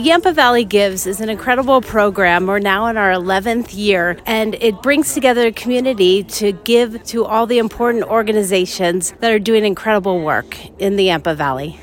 Nonprofits from Moffat County and the Yampa Valley gathered in Craig today to celebrate the 11th year of Yampa Valley Gives Day.